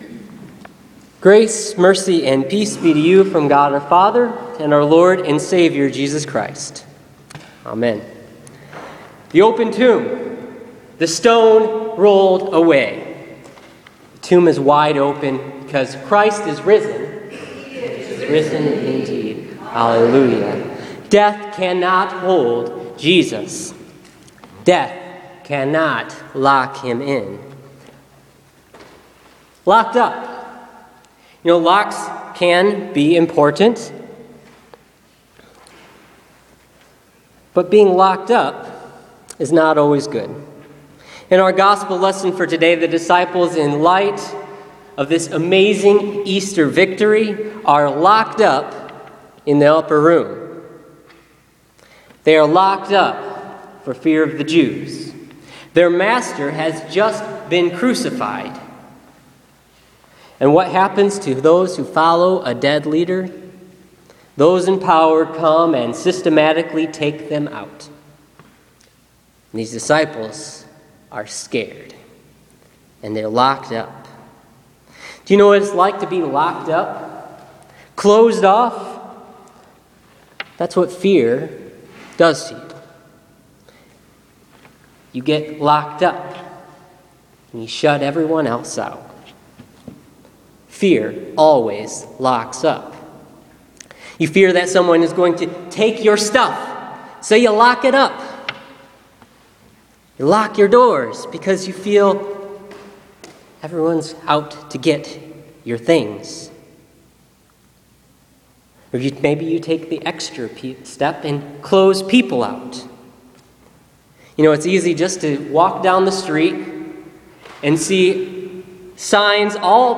Listen to this week’s sermon based on John 20:19-31 as Jesus appears to his disciples through locked doors. Jesus holds the Keys to life and death.